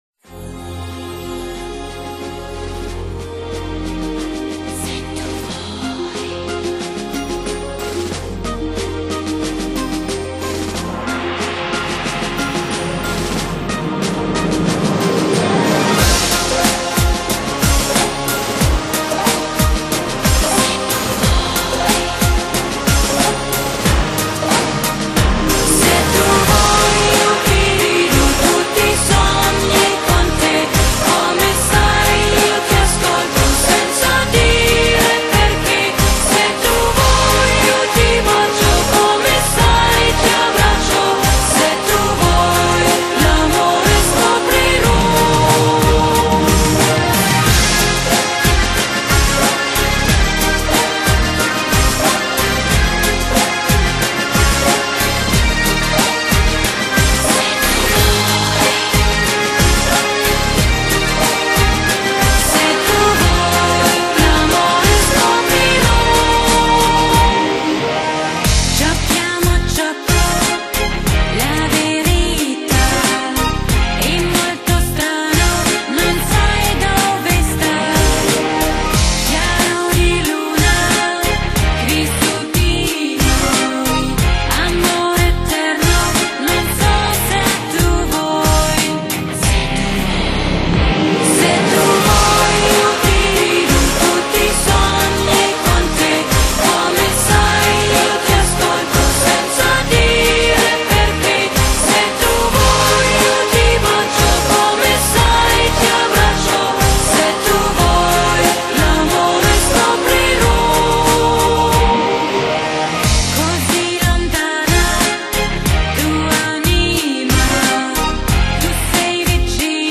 一种全新理念的酒吧音乐，时尚、刺激、有情调、氛围好，音乐风格是多样化、风格化，
低频富有弹性和丰满度，沉且力度适中，精